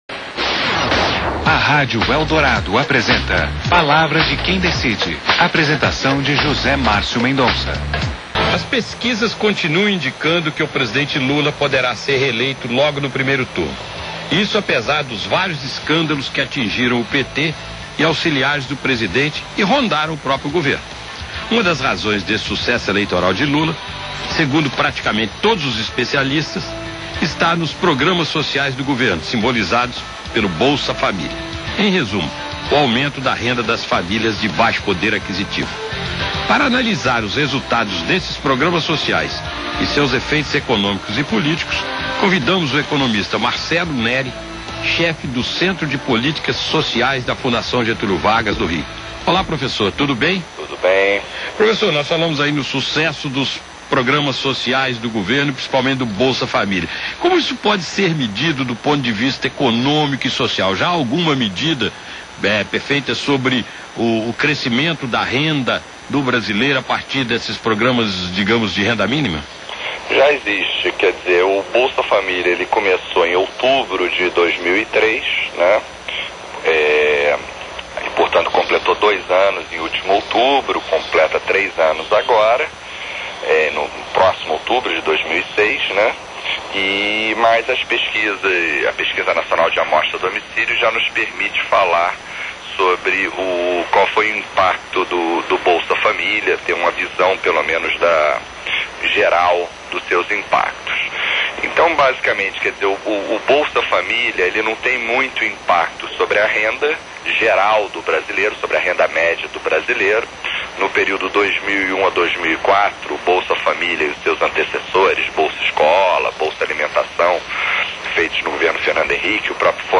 Entrevista com o professor da FGV Marcelo Néri, sobre sucesso do bolsa família
Rádio Eldorado - SP Mídia: Rádio